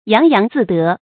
注音：ㄧㄤˊ ㄧㄤˊ ㄗㄧˋ ㄉㄜˊ
揚揚自得的讀法